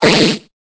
Cri de Barpau dans Pokémon Épée et Bouclier.